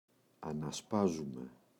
ανασπάζουμαι [ana’spazume]